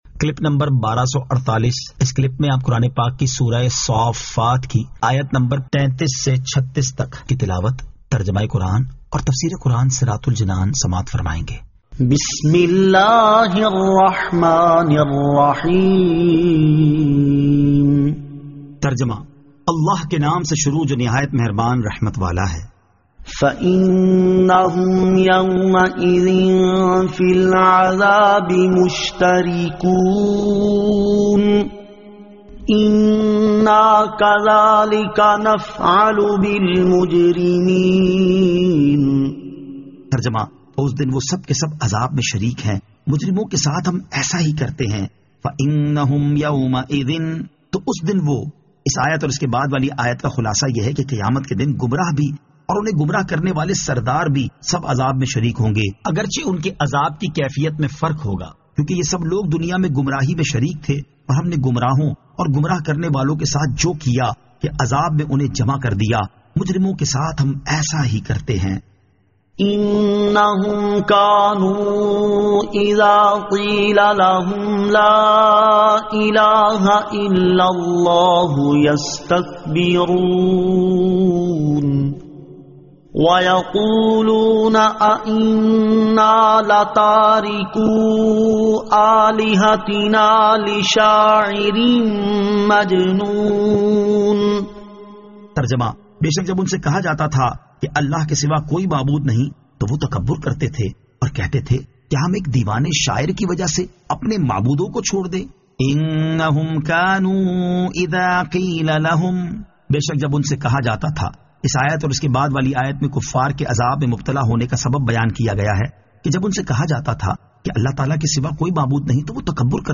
Surah As-Saaffat 33 To 36 Tilawat , Tarjama , Tafseer